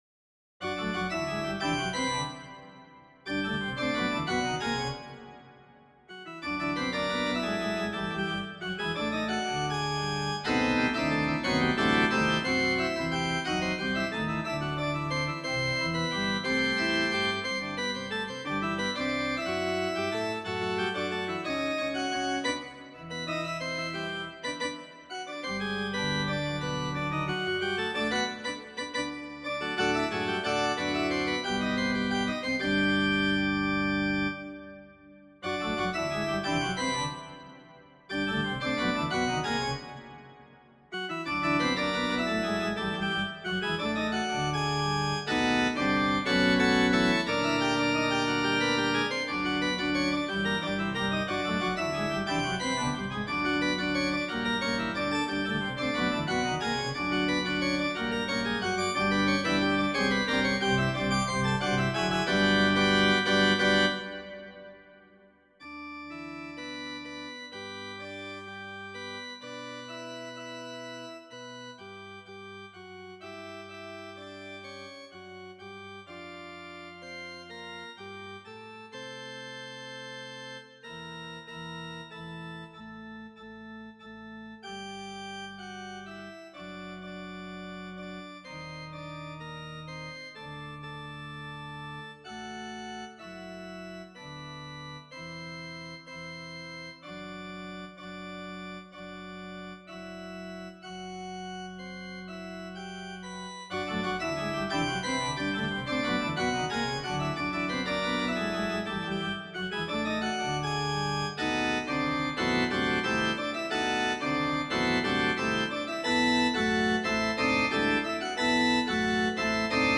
Prelude for Organ (2010)
mp3 (computer generated)